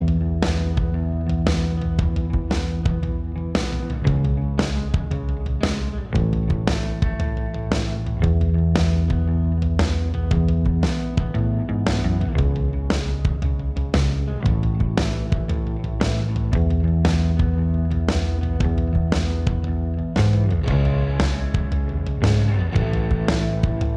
No Lead Guitar Rock 4:12 Buy £1.50